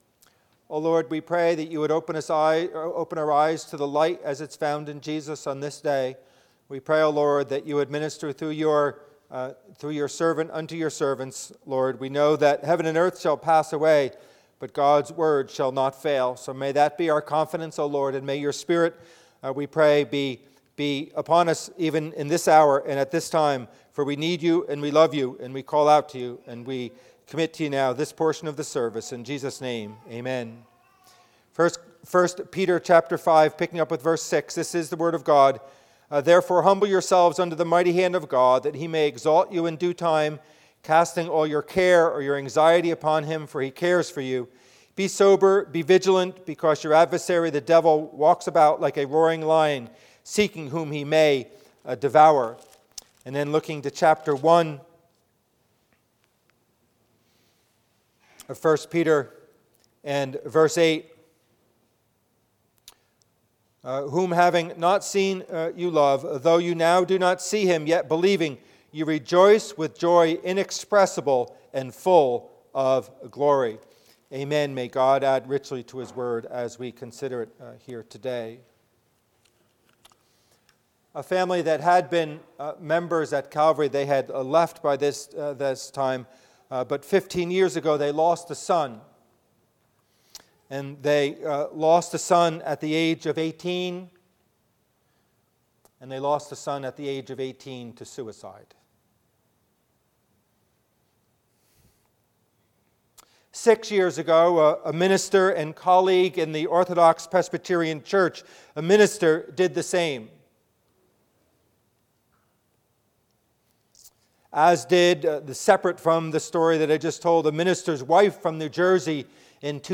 Sermon: You Are an Emotional Being. Are You A Sanctified and Mature Emotional Being? Part IV